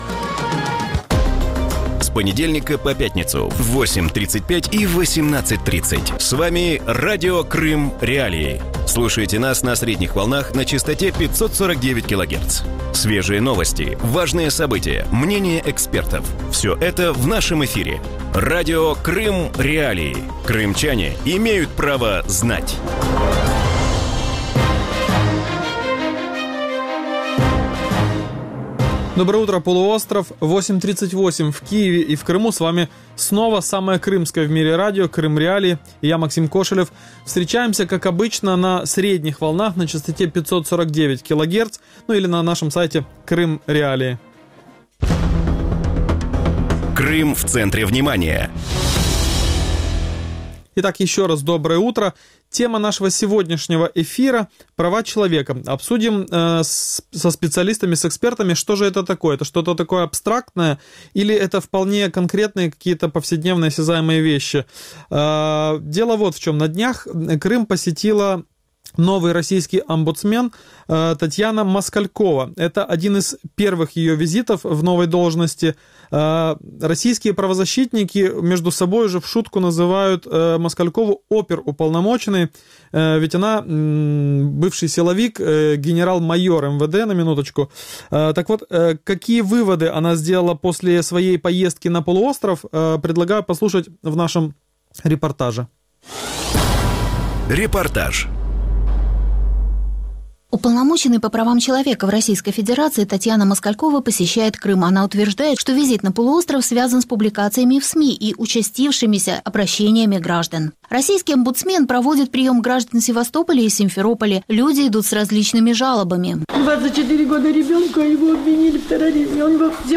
Утром в эфире Радио Крым.Реалии обсуждают недавний визит на полуостров уполномоченной по правам человека в России Татьяны Москальковой. Одним из предложений омбудсмена стало государственное регулирование цен на продукты в Крыму.